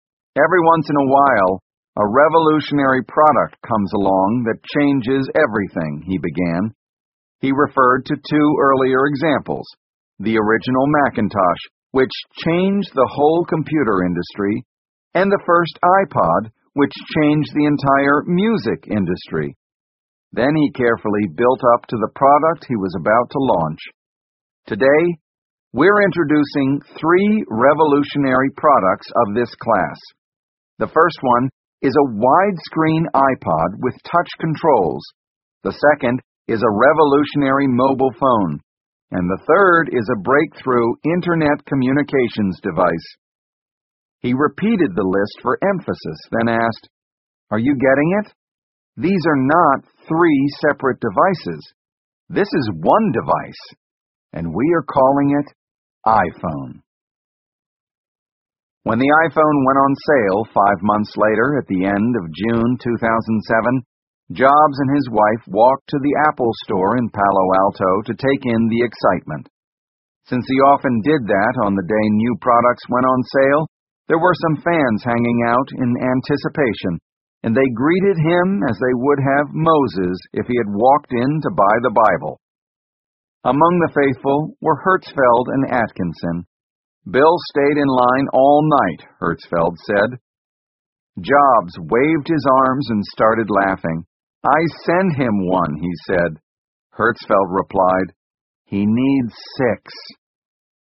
在线英语听力室乔布斯传 第656期:发布(2)的听力文件下载,《乔布斯传》双语有声读物栏目，通过英语音频MP3和中英双语字幕，来帮助英语学习者提高英语听说能力。
本栏目纯正的英语发音，以及完整的传记内容，详细描述了乔布斯的一生，是学习英语的必备材料。